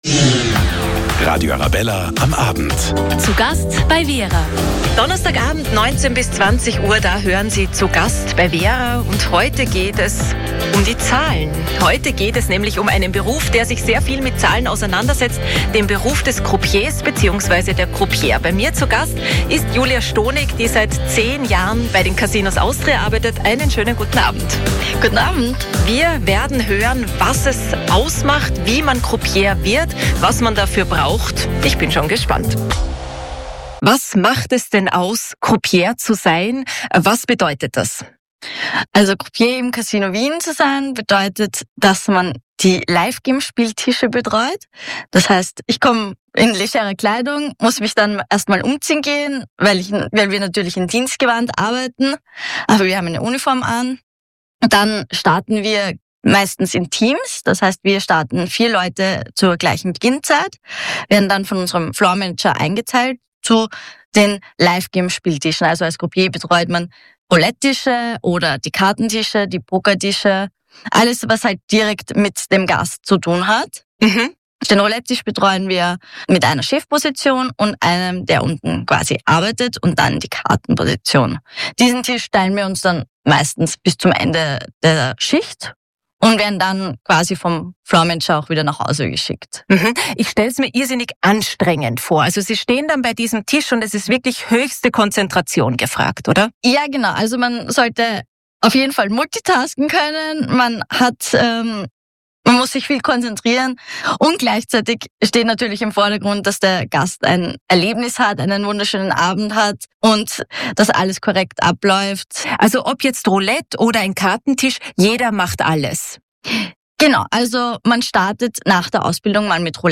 Der Expertentalk zu den wichtigsten und spannendsten Themen aus den Bereichen Wirtschaft, Kultur und Politik!